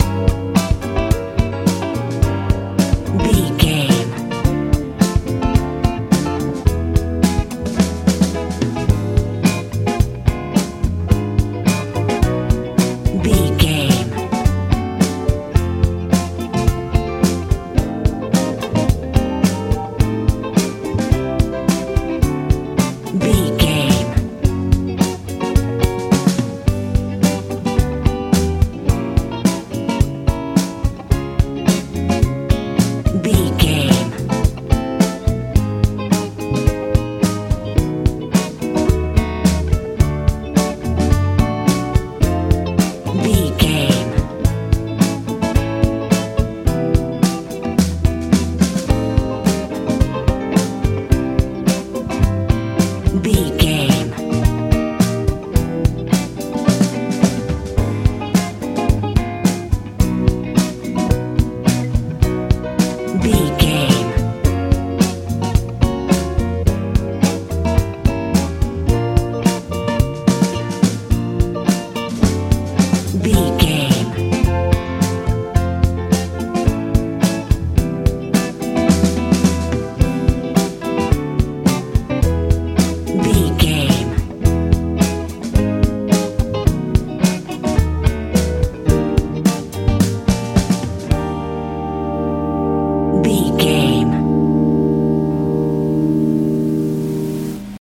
70s rnb feel
Aeolian/Minor
B♭
energetic
lively
electric guitar
bass guitar
drums
piano
soft
smooth
soothing